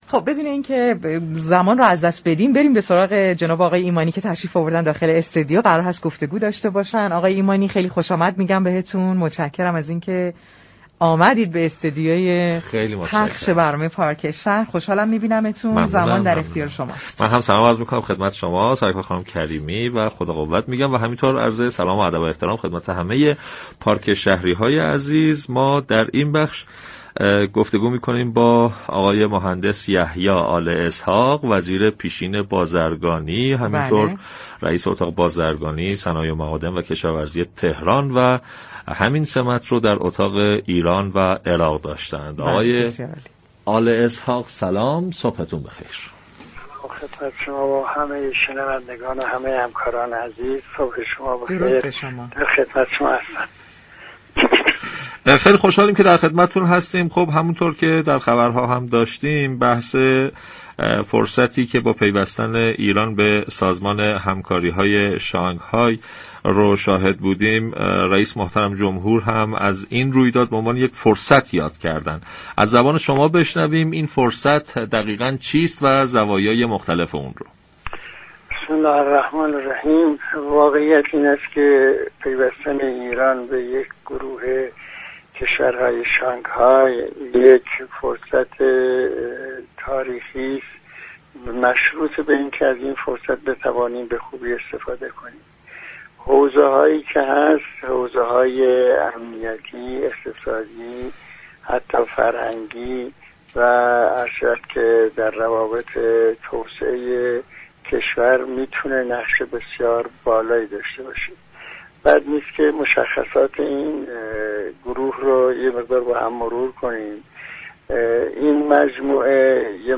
به گزارش پایگاه اطلاع رسانی رادیو تهران، یحیی آل اسحاق وزیر پیشین بازرگانی در گفتگو با پارك شهر رادیو تهران در خصوص پیوستن ایران به سازمان همكاری‌های شانگهای گفت: واقعیت این است كه پیوستن ایران به سازمان همكاری‌های شانگهای یك فرصت تاریخی است مشروط بر اینكه بتوانیم از این فرصت به‌خوبی استفاده كنیم.